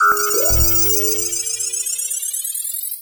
sci-fi_power_up_04.wav